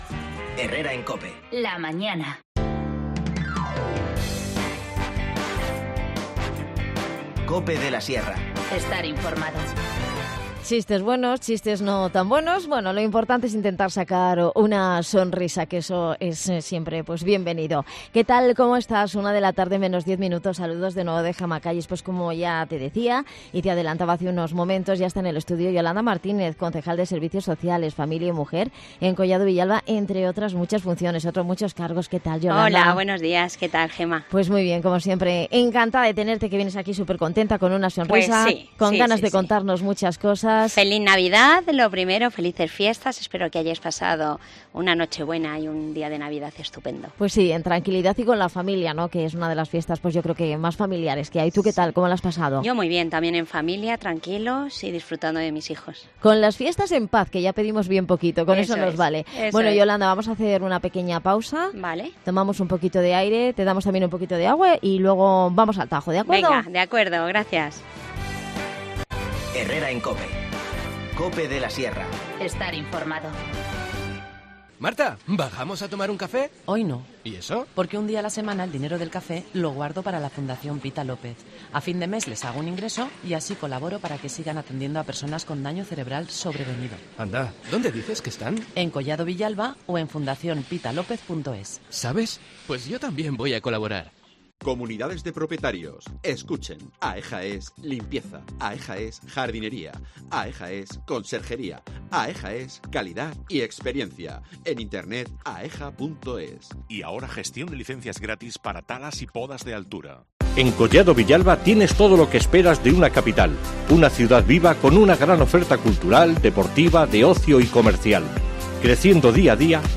Repasamos toda la programación que ha preparado el ayuntamiento de Collado Villalba para estas fiestas con Yolanda Martínez, concejal de Servicios Sociales, Familia y Mujer